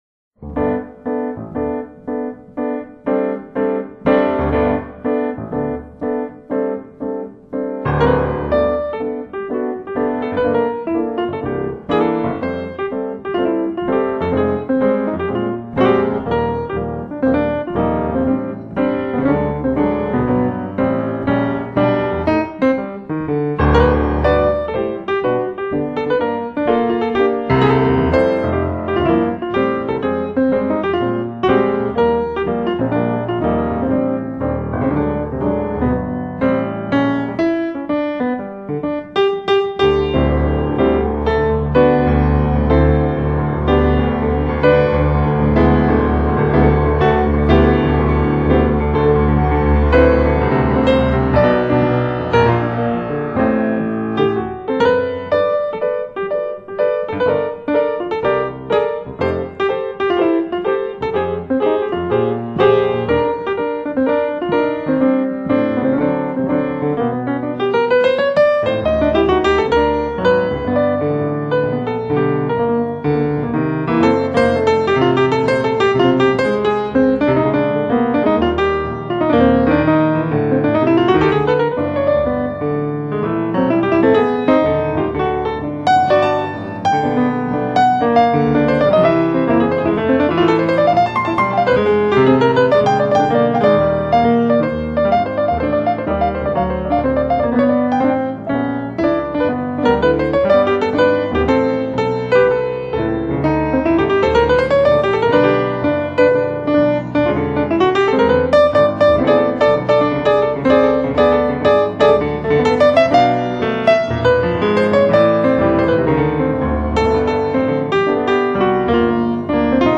【爵士雙奏】
極品爵士樂鋼琴二重奏巔峰傑作！
這張二重奏十分好聽，豐滿、甜美而晶瑩剔透，尤其是夜晚品嘗，更是無敵。